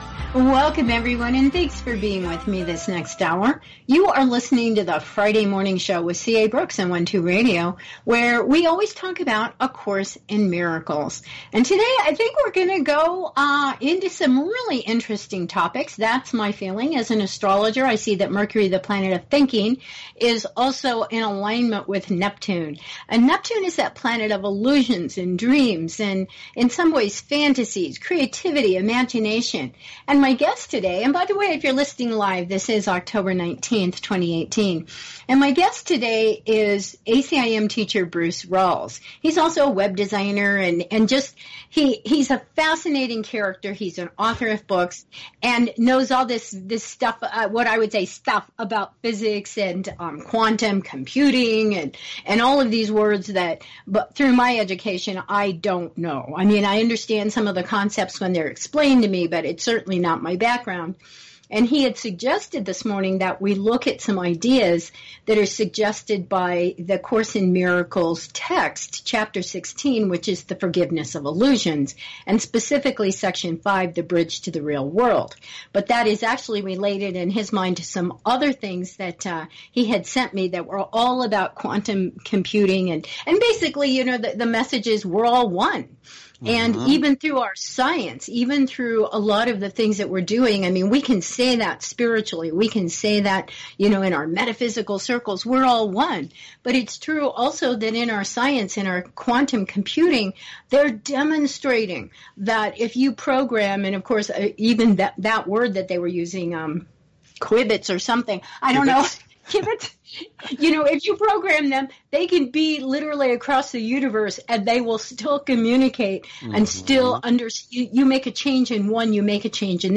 "Bridging the Gap" - A conversation